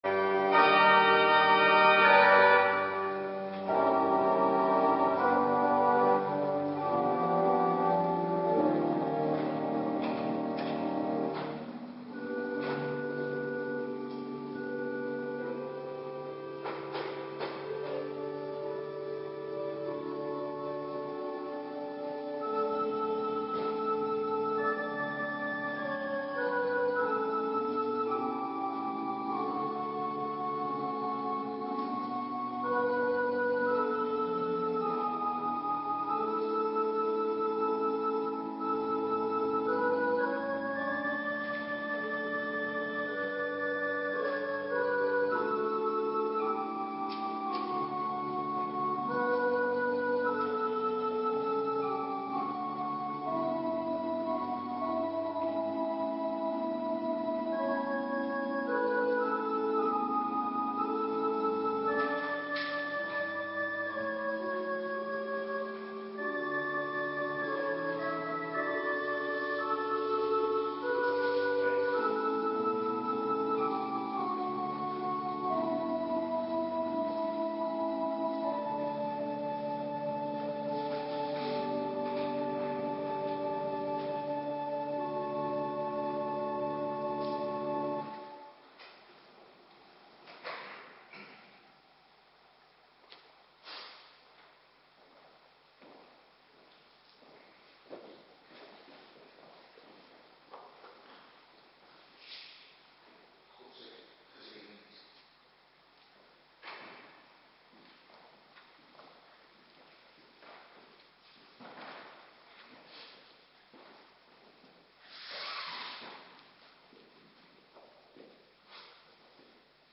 Morgendienst Tweede Paasdag
Locatie: Hervormde Gemeente Waarder